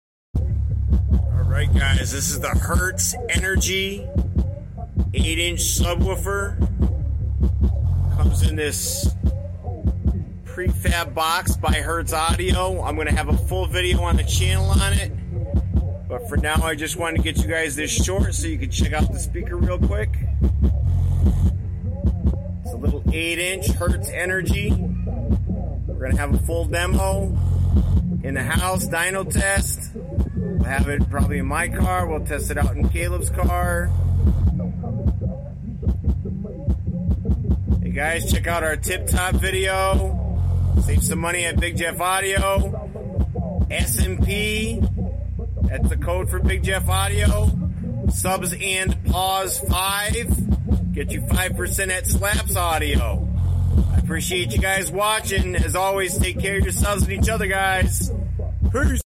Hertz Energy 8 EBX 200 Sub Flex